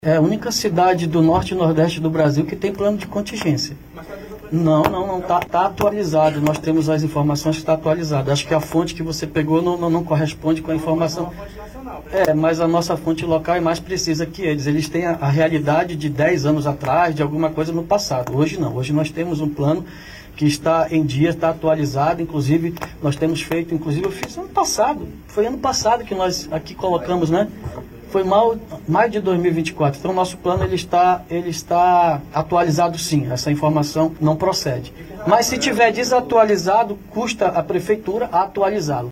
Questionado durante coletiva de imprensa nesta quinta-feira, 20, o prefeito David Almeida (Avante) chegou a informar que o plano estaria atualizado.